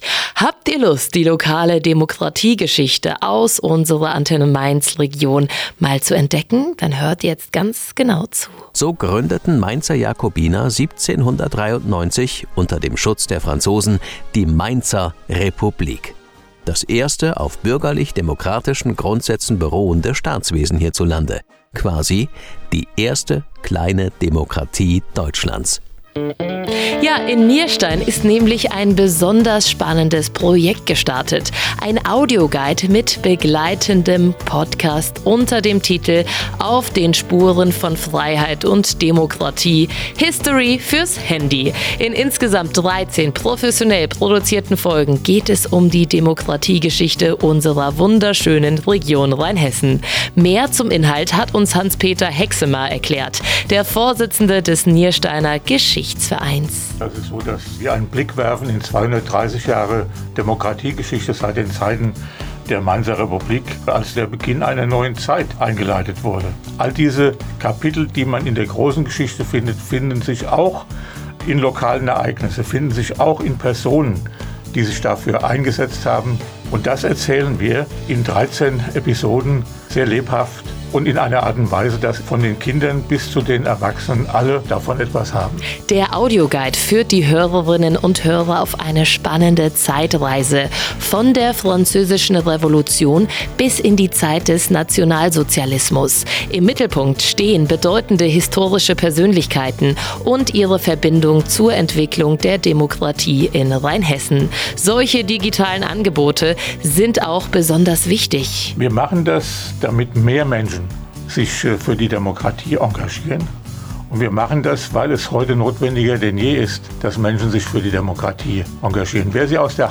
Interview bei Antenne Mainz (6,5 MB, 3:28 min.)